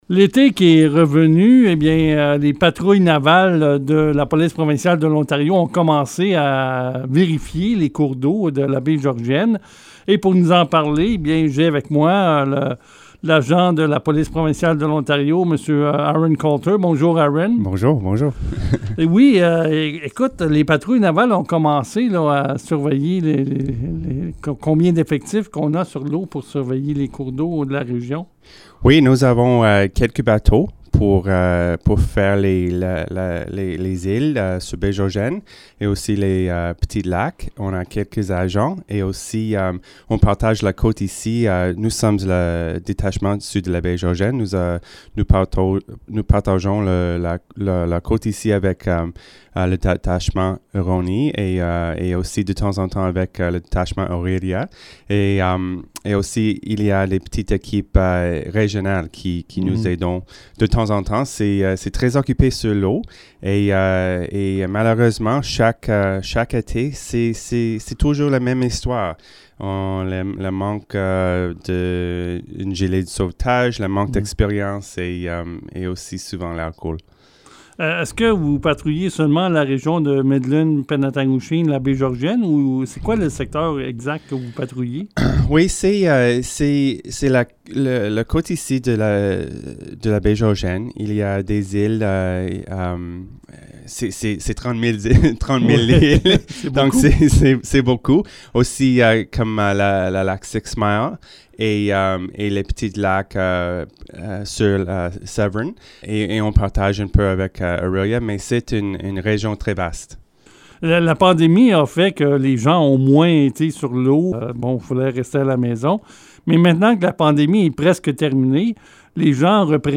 est venu dans les studios de CFRH afin de faire la lumière sur tout ça.